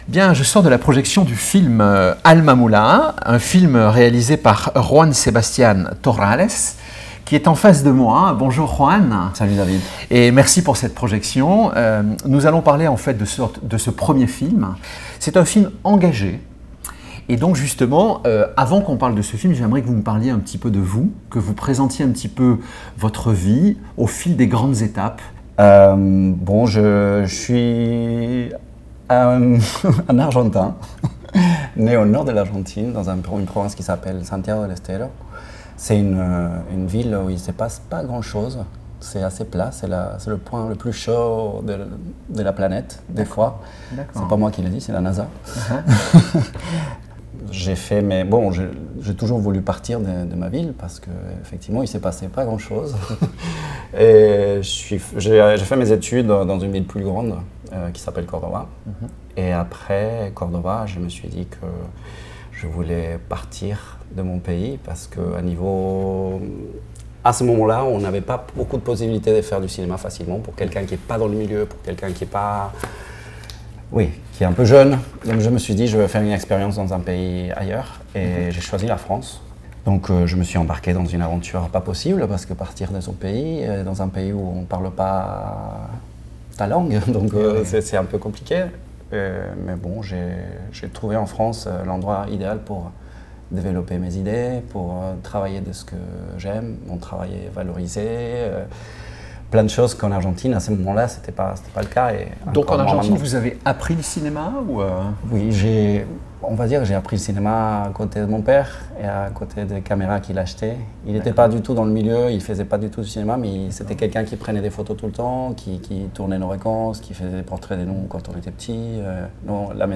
%%Les podcasts, interviews, critiques, chroniques de la RADIO DU CINEMA%%